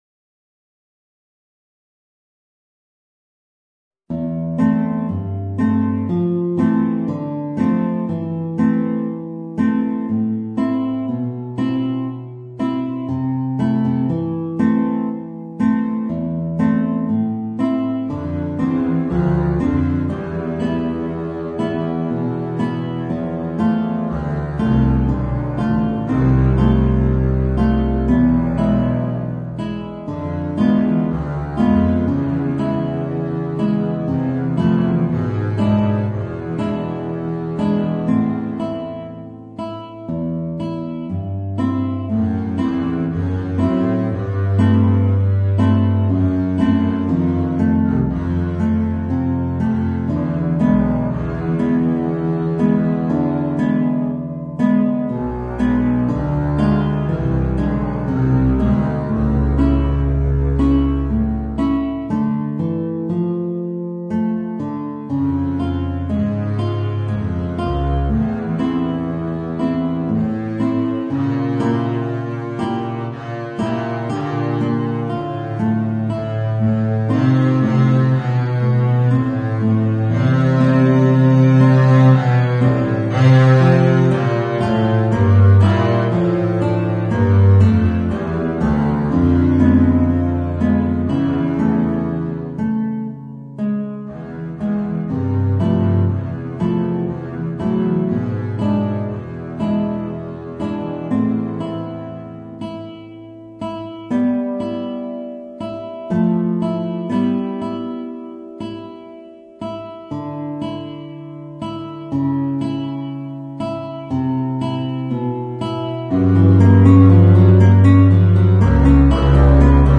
Voicing: Guitar and Contrabass